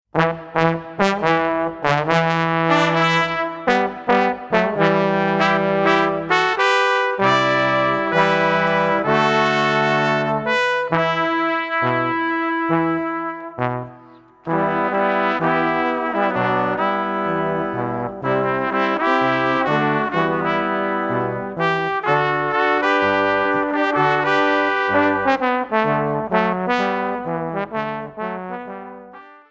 Wonderful settings of Luther songs in the typical swinging